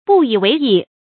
不以為意 注音： ㄅㄨˋ ㄧˇ ㄨㄟˊ ㄧˋ 讀音讀法： 意思解釋： 不把它放在心上。表示對人、對事抱輕視態度。